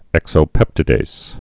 (ĕksō-pĕptĭ-dās, -dāz)